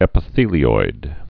(ĕpə-thēlē-oid)